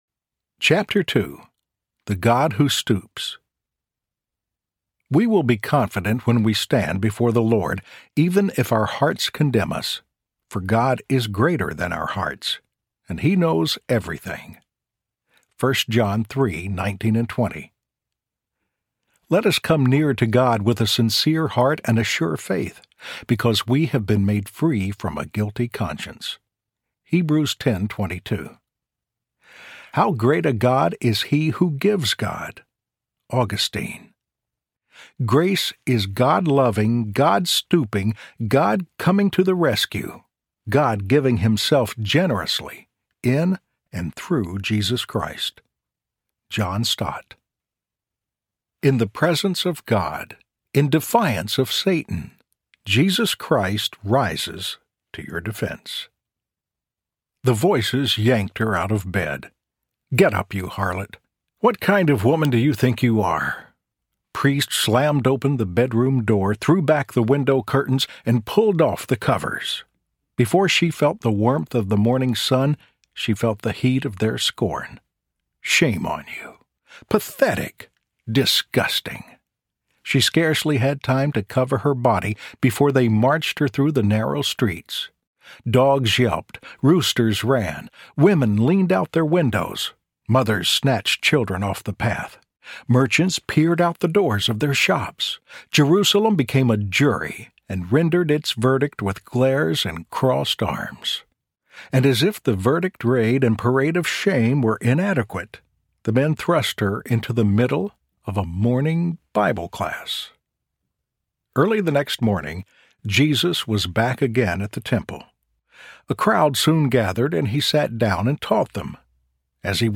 Grace Audiobook
Narrator
2.6 Hrs. – Unabridged